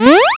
jump.wav